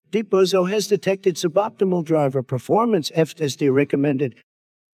deep-bozo-has-detected.wav